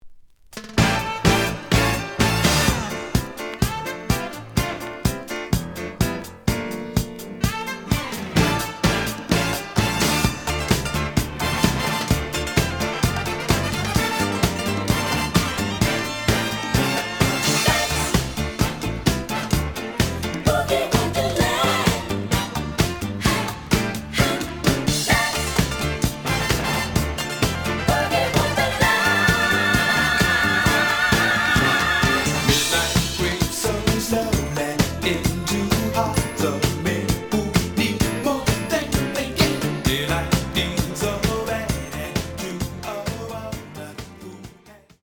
(Mono)
試聴は実際のレコードから録音しています。
●Genre: Disco